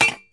Bells / Gongs » Gas Bottle
描述：A stereo recording of a small propane gas bottle struck with a rubberised handle.. Rode NT4 > Fel battery Preamp > Zoom H2 linein.
标签： clang bell metal percussion stereo xy
声道立体声